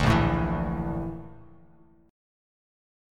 A#M#11 chord